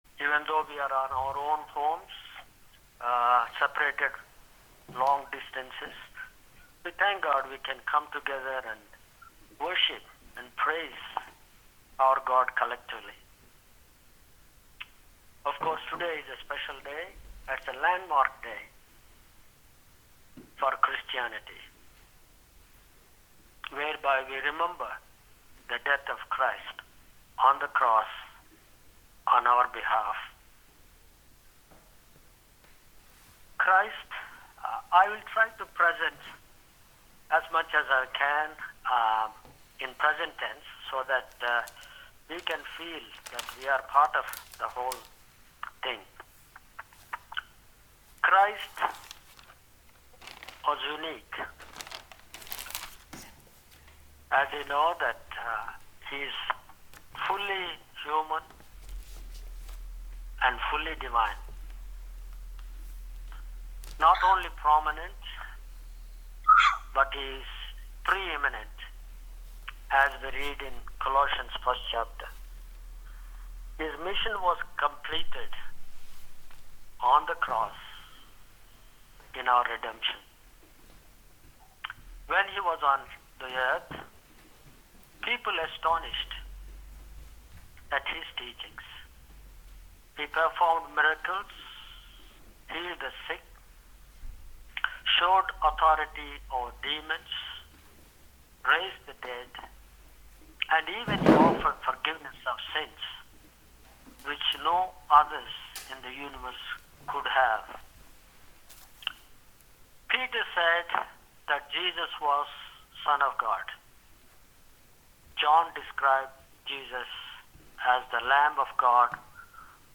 Bible Text: John 19: 25-30 | Preacher: